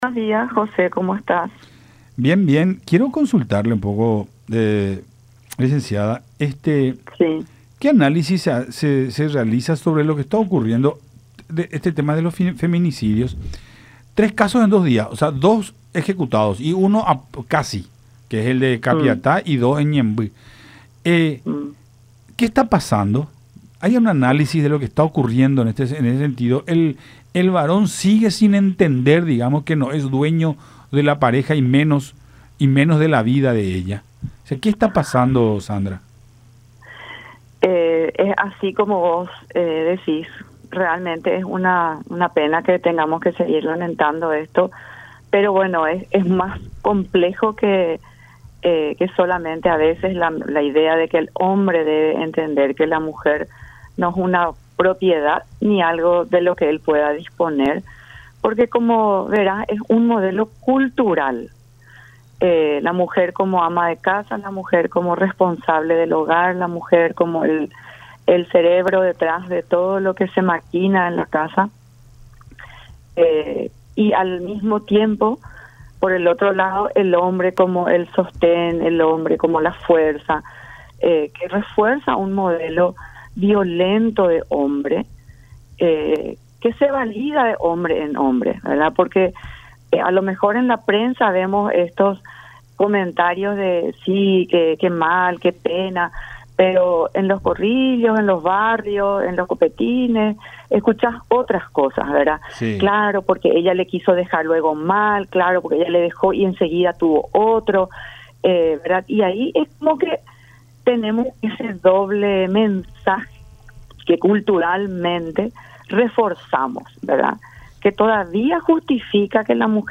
en contacto con Nuestra Mañana a través de Unión TV y radio La Unión.